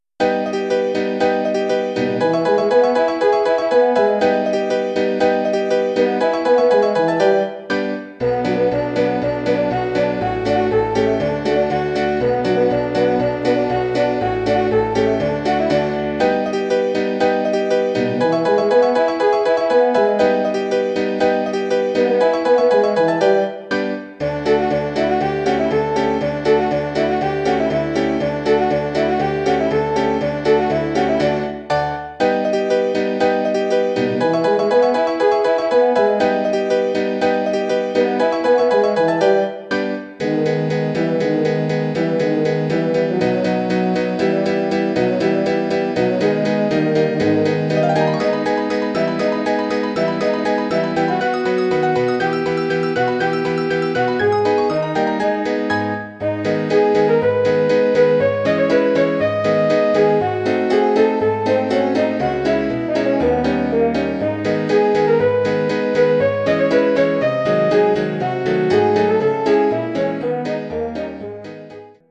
Bearbeitung für Horn und Klavier
Besetzung: Horn, Klavier
Arrangement for horn and piano
Instrumentation: horn, piano